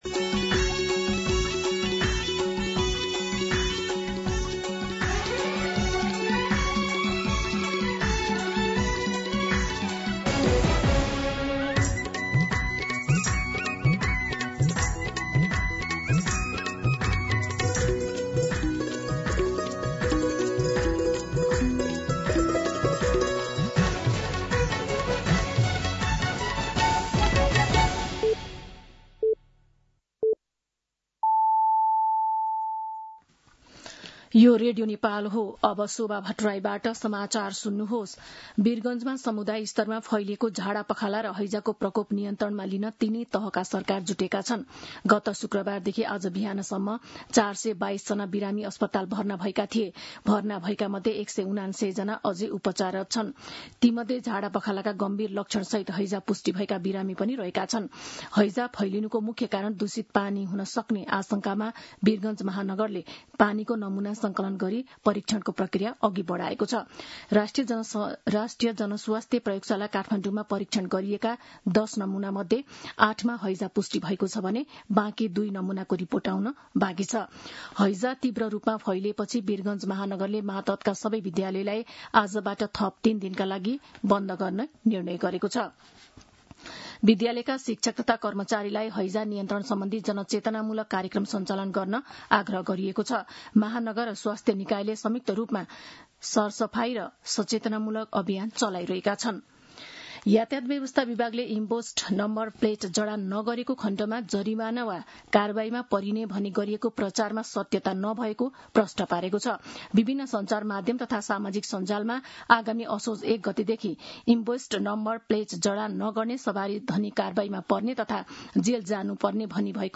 दिउँसो १ बजेको नेपाली समाचार : ११ भदौ , २०८२